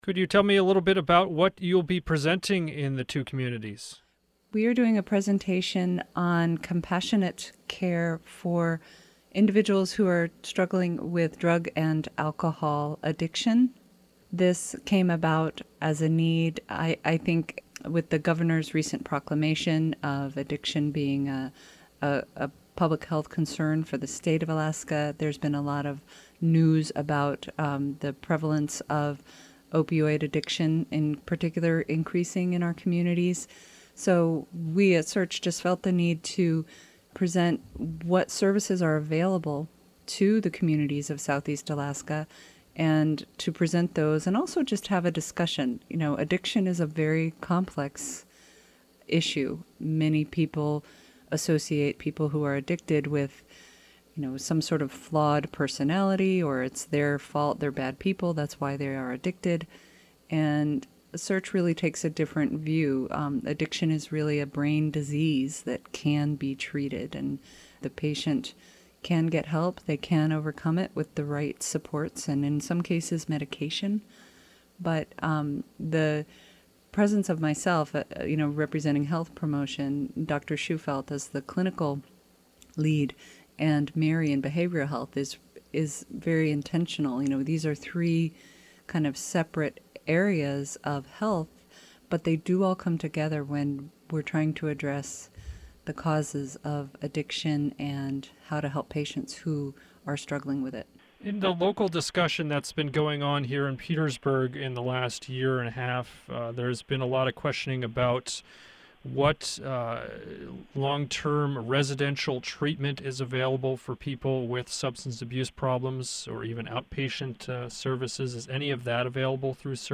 Representatives from the Southeast Alaska Regional Health Consortium, or SEARHC (pronounced SEARCH) are holding discussions in Petersburg and Wrangell this week on drug and alcohol addiction and resources that are available in the two communities.